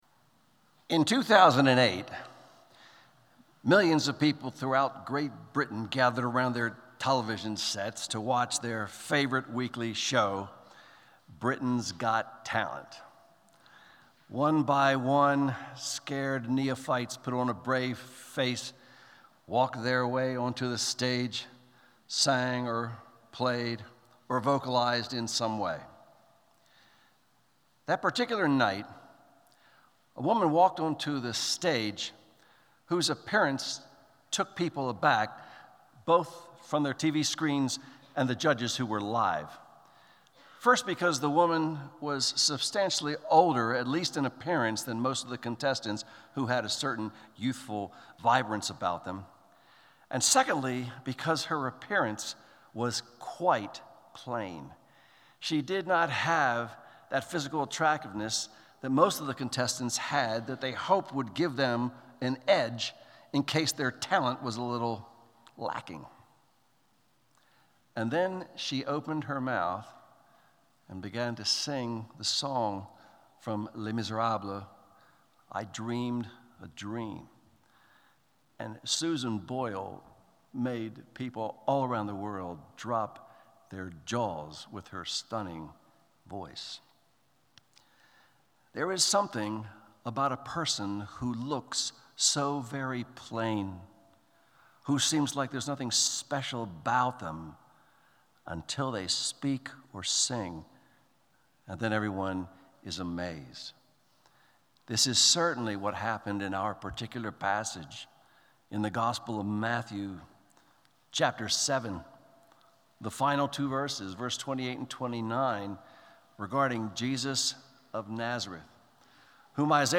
Sermons on Matthew 7:28-29 — Audio Sermons — Brick Lane Community Church